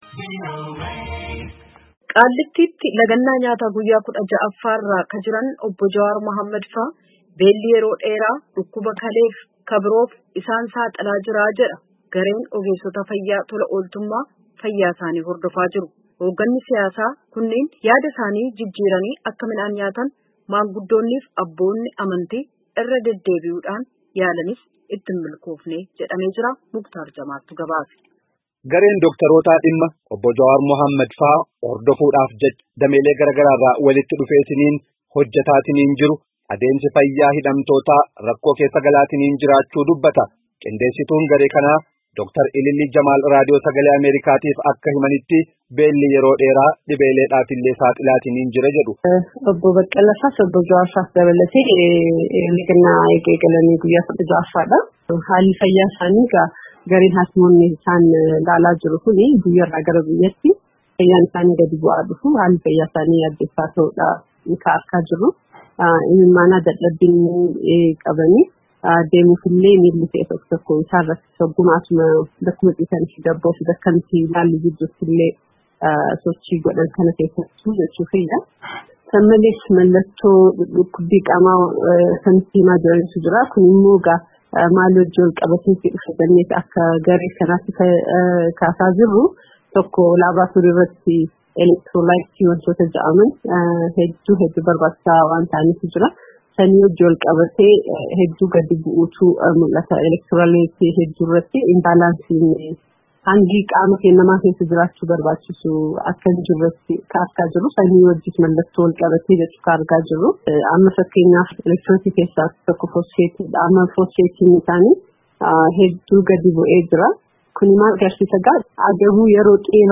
Gabaasaa guutuu caqasa.